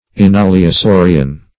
Search Result for " enaliosaurian" : The Collaborative International Dictionary of English v.0.48: Enaliosaurian \En*al`i*o*sau"ri*an\, a. (Paleon.)